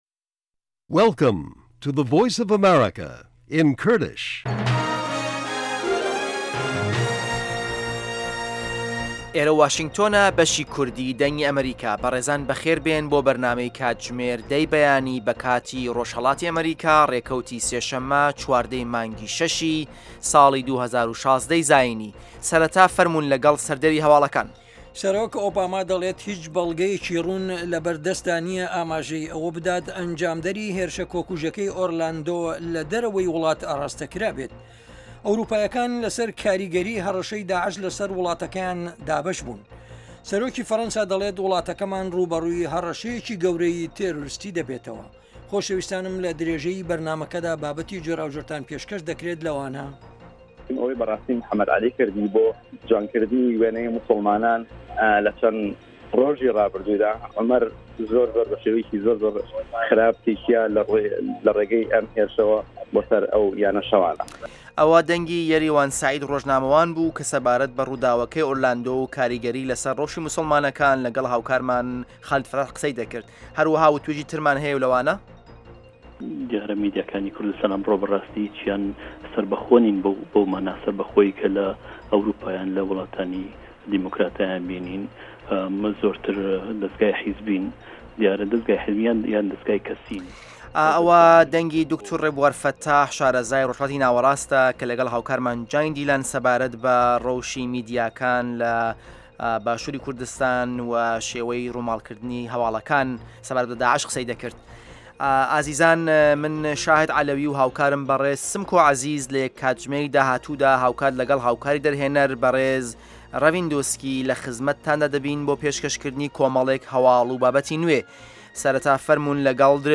هه‌واڵه‌کان، ڕاپـۆرت، وتووێژ و پاشان به‌رنامه‌ی هه‌فتانه‌ی "ژیان له‌ فه‌رهه‌نگدا"